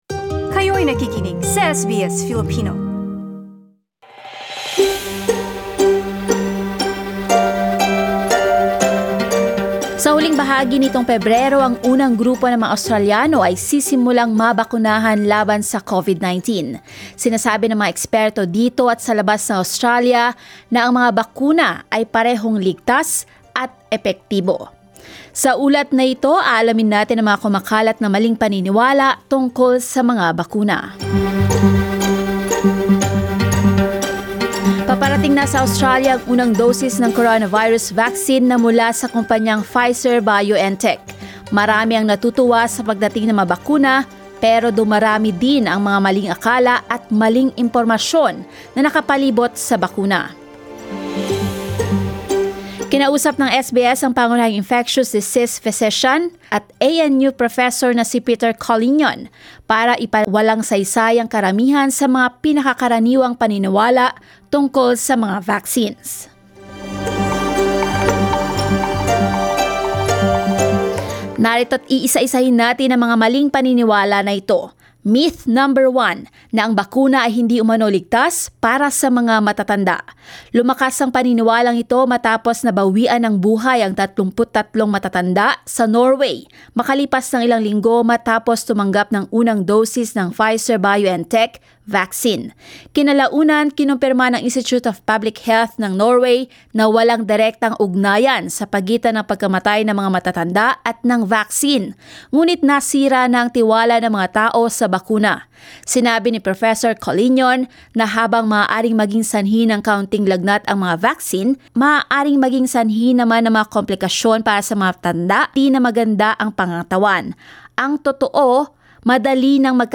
Experts at home and abroad tell us our vaccines are both safe and effective. But, as this report, many myths about the vaccines are still circulating unchecked.